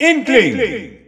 Announcer pronouncing Inkling in French.
Inkling_French_Announcer_SSBU.wav